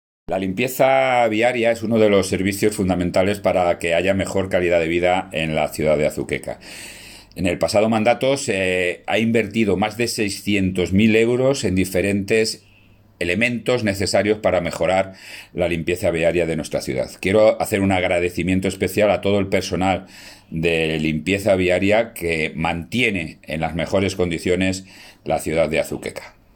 Declaraciones del alcalde José Luis Blanco 1